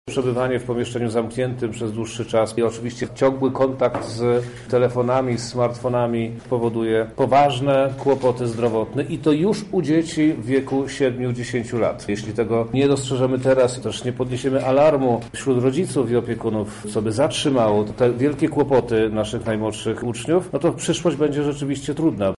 Wyniki są porażające i duży wpływ miała na to nauka zdalna – mówi minister edukacji Przemysław Czarnek: